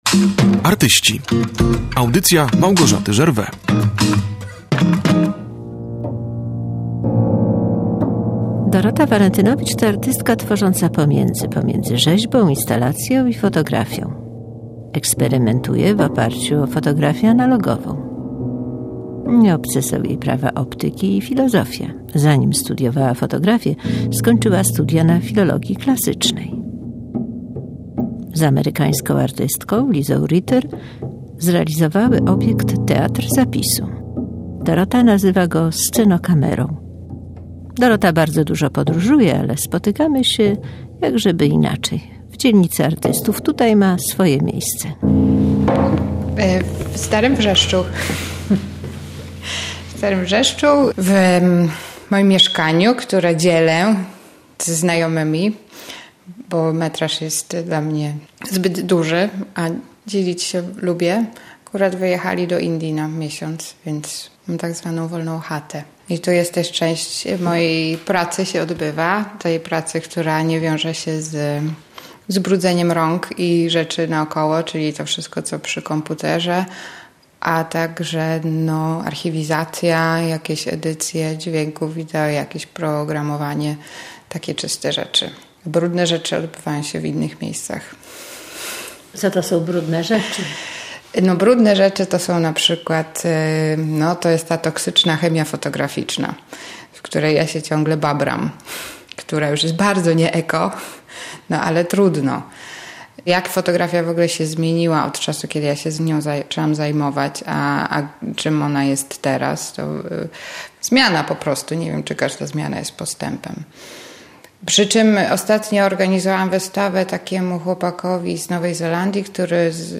dokument kultura